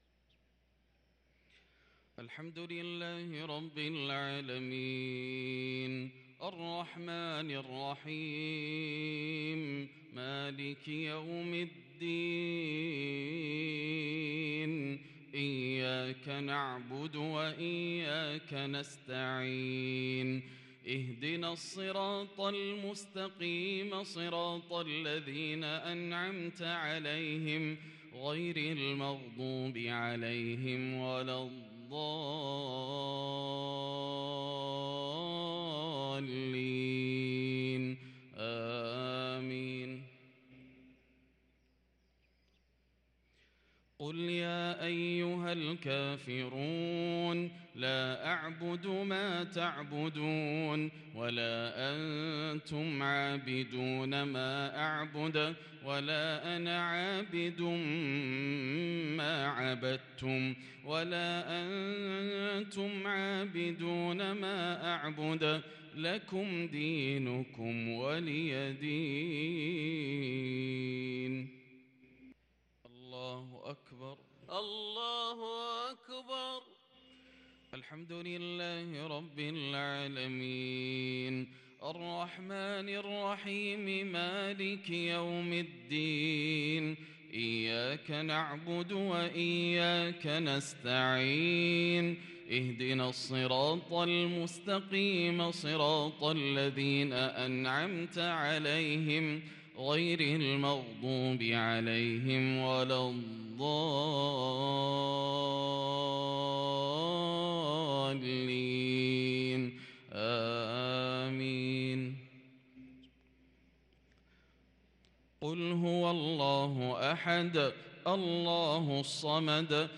صلاة المغرب للقارئ ياسر الدوسري 22 جمادي الأول 1444 هـ
تِلَاوَات الْحَرَمَيْن .